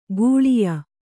♪ gūḷiya